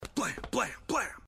Descarga de Sonidos mp3 Gratis: laser shiro.
ringtones-laser-shiro.mp3